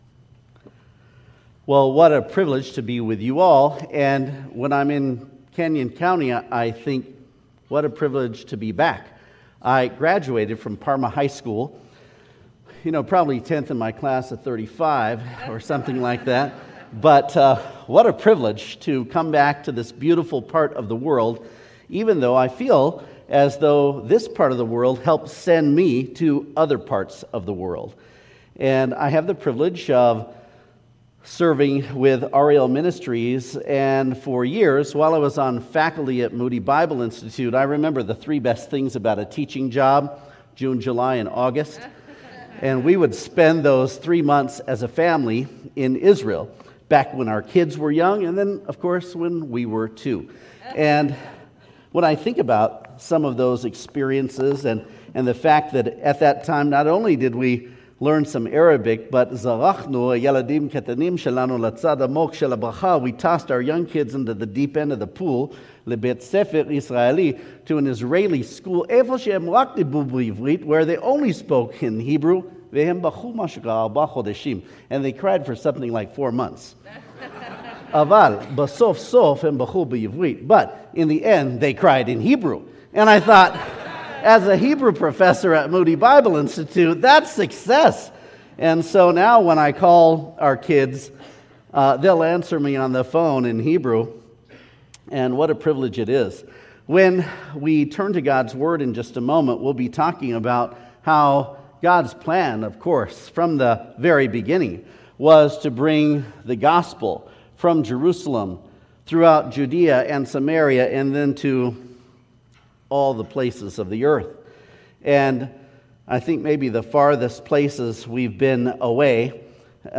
Sermons | New Hope Baptist Church